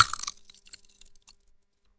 Crunch! …That loud, crisp snap as my teeth sink into a fresh, juicy apple.
The skin breaks, the flesh pops, and the sound echoes in the silence.
Each bite is clean, sharp, and satisfying.”
오히려 과자를 먹을 때 생기는 소리도 있는 것 같고 가장 사과를 베어 물때 어떤 효과음이 잘 어울릴지 고민하고 선택하시면 되겠죠?